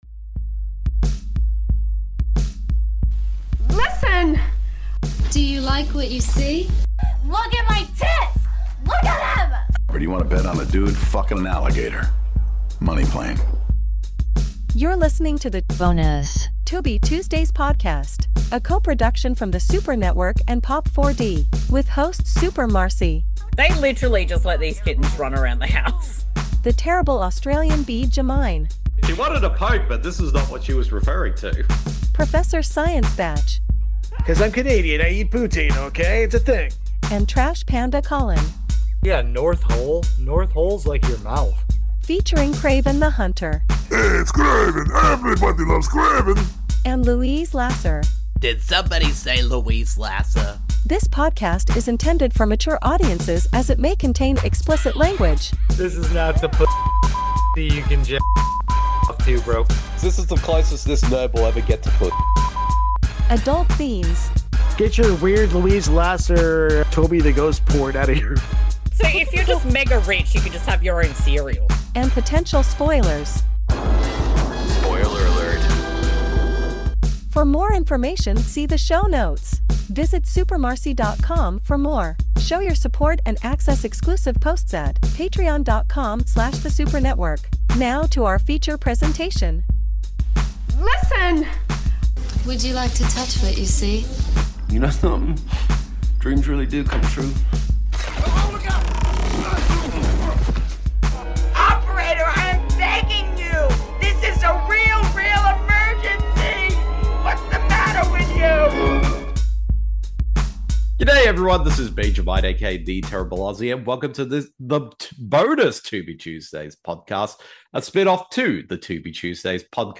Much like with Tubi Tuesdays the podcast is focused on discovering and doing commentaries/watch a longs for films, the more random and weird the better!